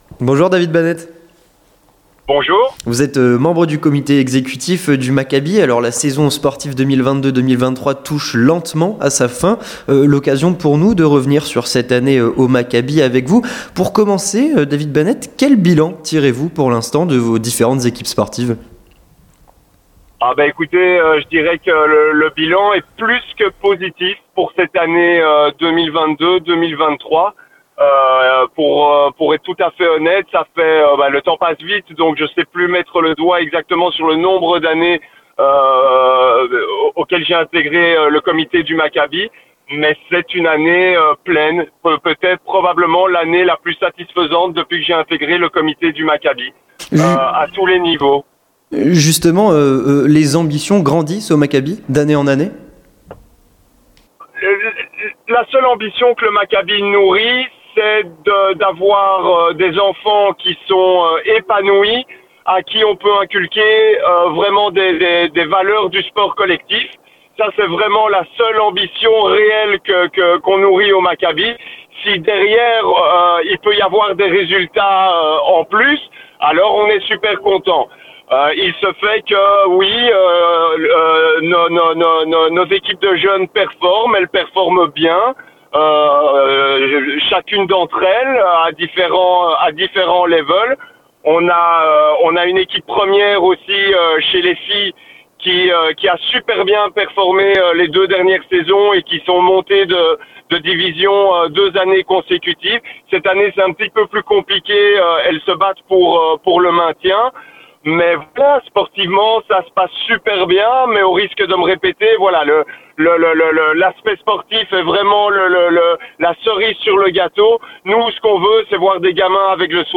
L'interview communautaire - La saison du Maccabi et le retour du grand tournoi annuel